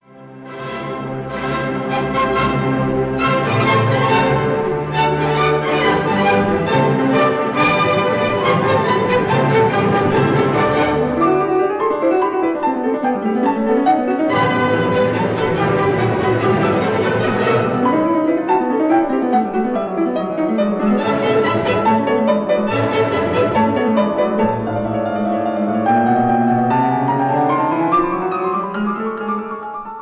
conductor